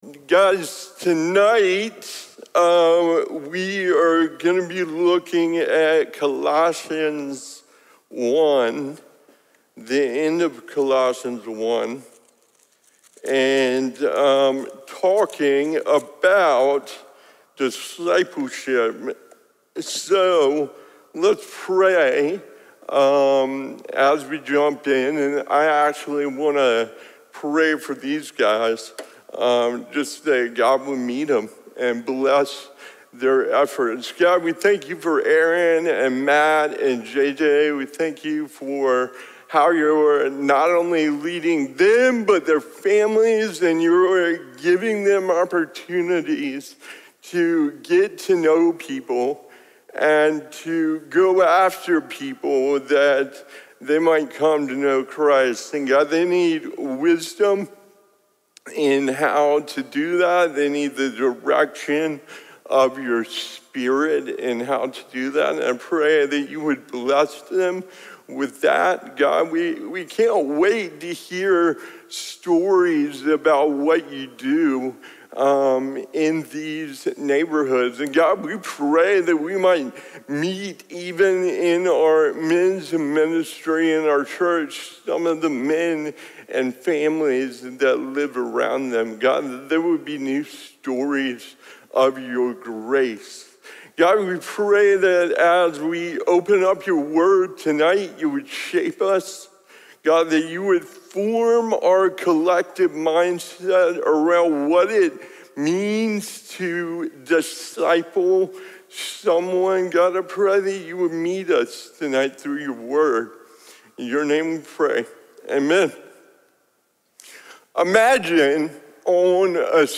Audio from men's gatherings at Crossway Community Church.